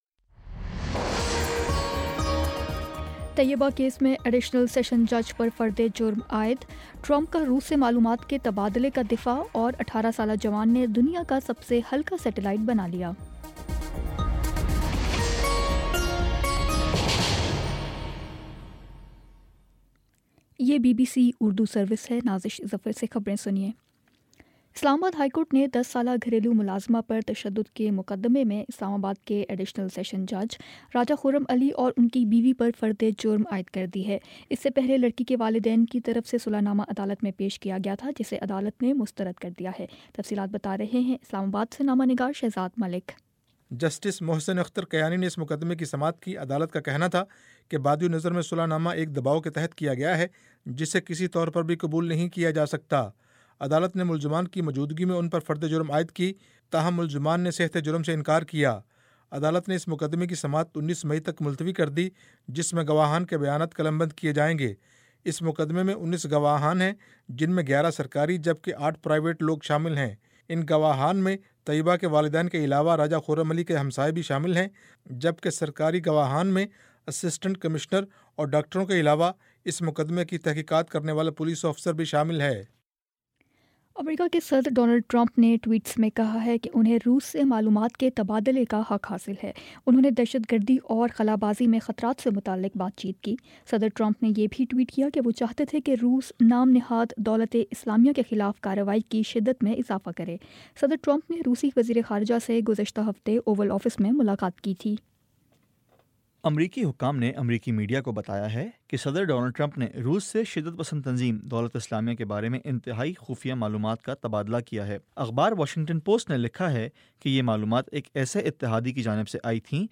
مئی 16 : شام سات بجے کا نیوز بُلیٹن